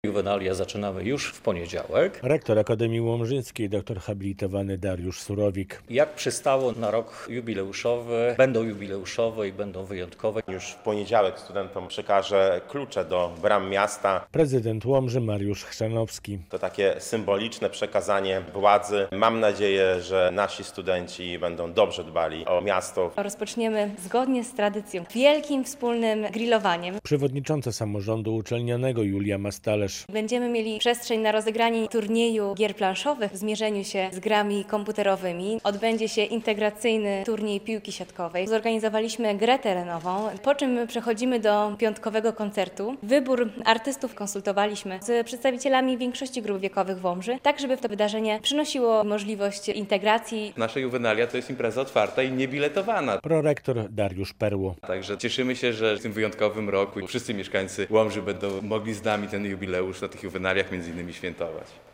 Zbliżają się juwenalia w Łomży - relacja